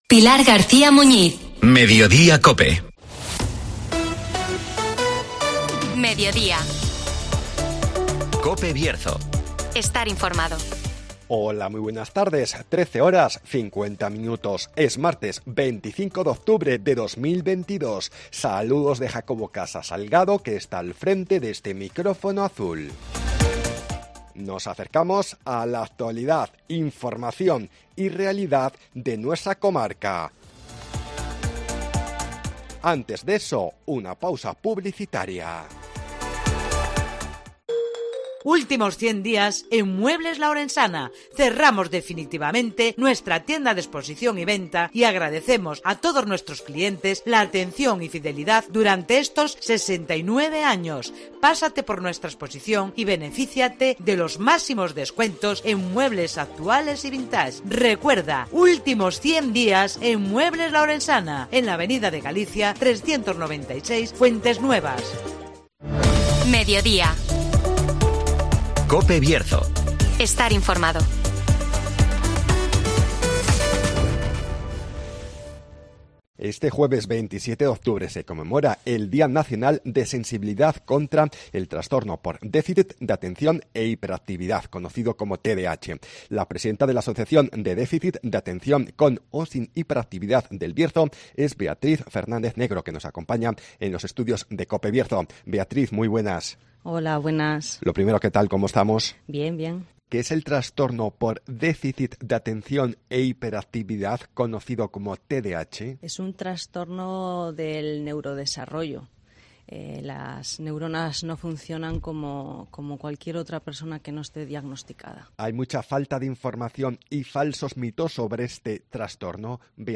Este jueves se celebra también en Ponferrada el día nacional del Tdah (Entrevista